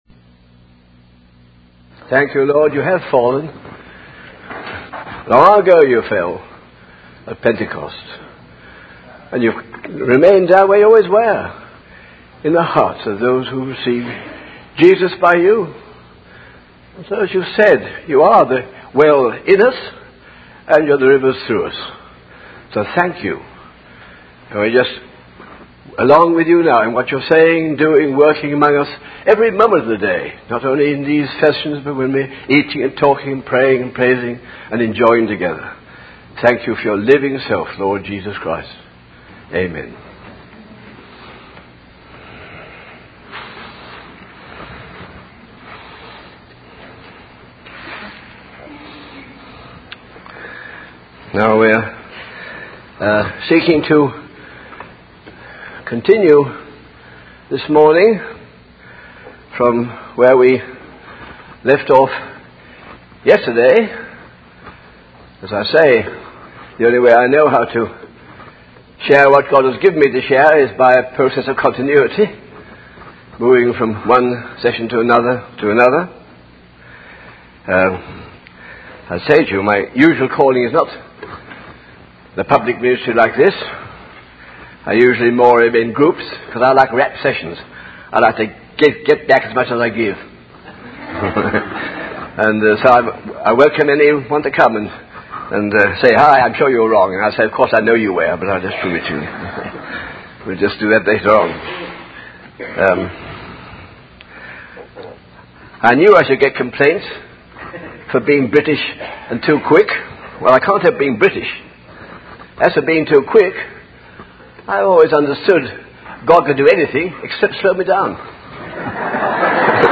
In this sermon, the preacher discusses the concept of slavery and how it has been the eternal purpose of God.